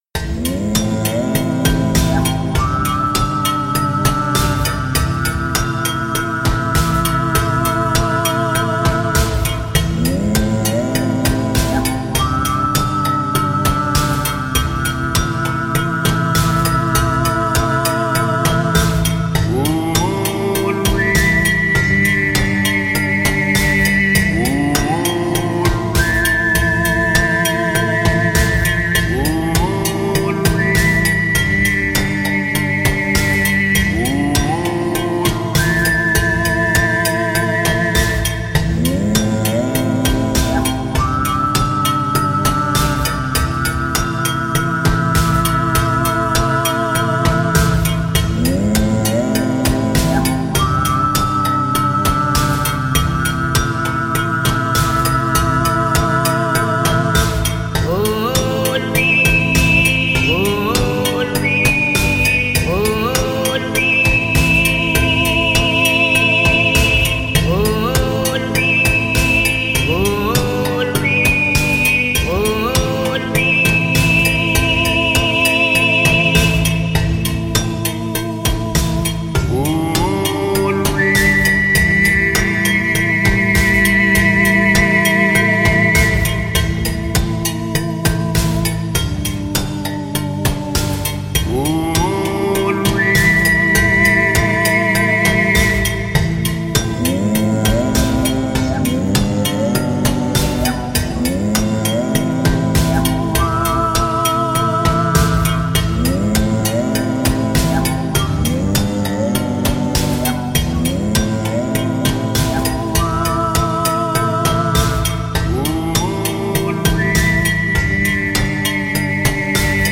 不穏で怪しい感じの曲です。【BPM100】